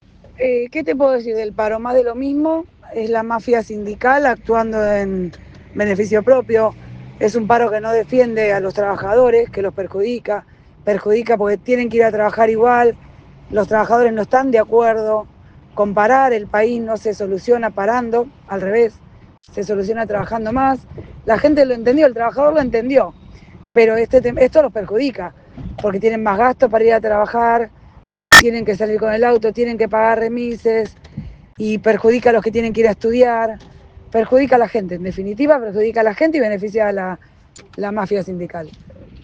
Desde Argentina Política, conversamos con diferentes actores afines al gobierno Nacional.
Natalia Quiñoa presidenta del Bloque Nuevo PRO San Martín.